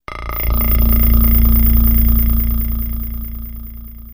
Video Game Transition Effect